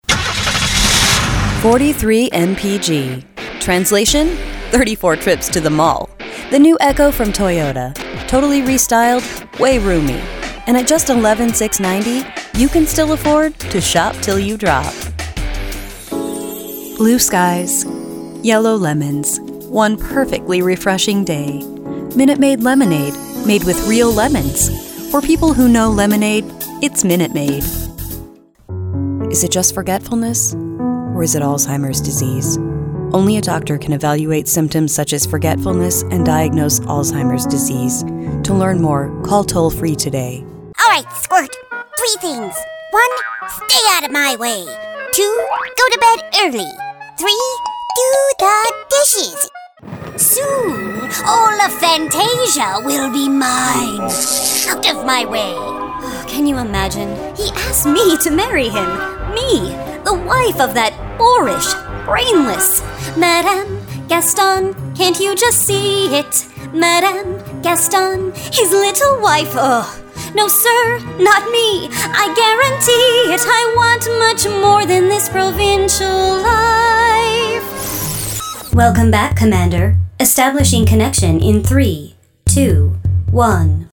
Native speaker Female 30-50 lat
Nagranie lektorskie